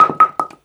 bowlingPinFall_r_2.wav